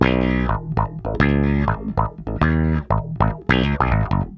Bass Lick 34-02.wav